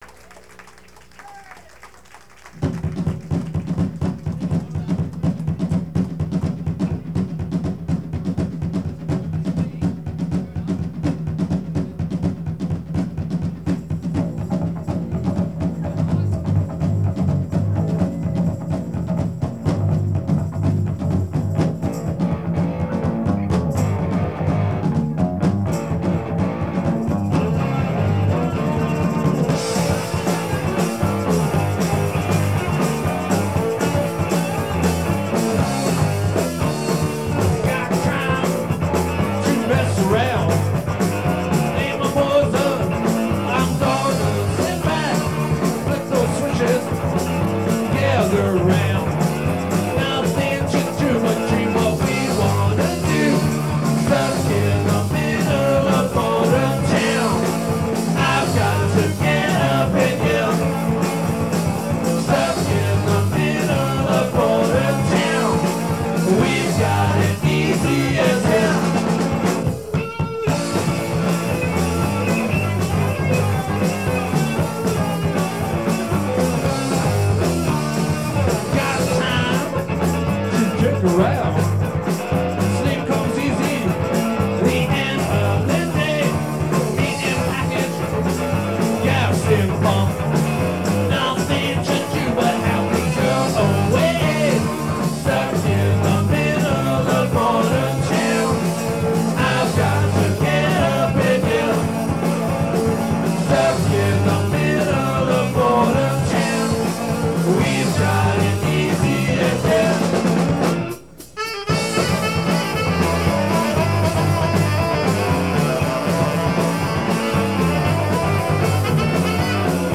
I remember the band really liked to cook on this song.
tenor saxophone, percussion
guitar, vocals